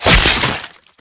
HitOrganic.wav